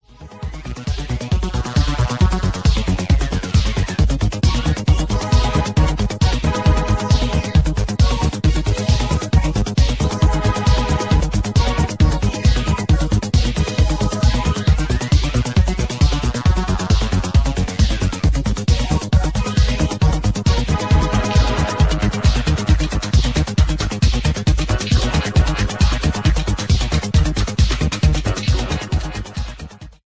disco track